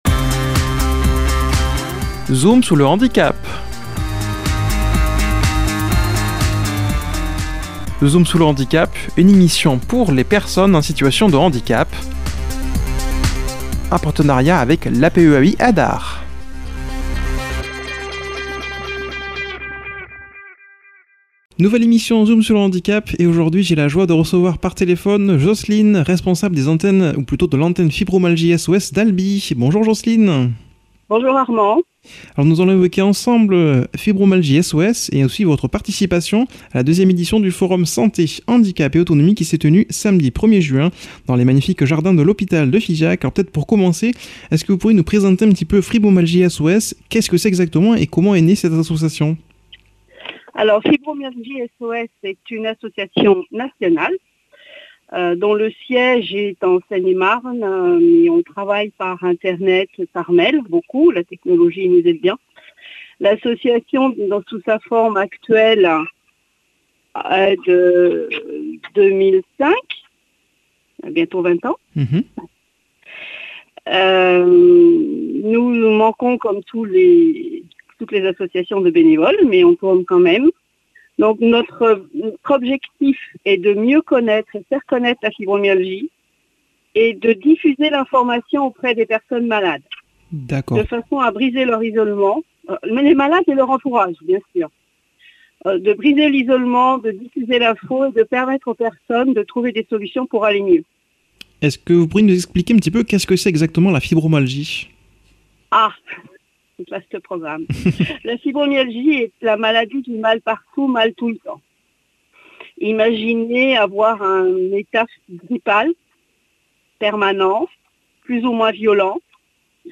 Rediffusion
invitée par téléphone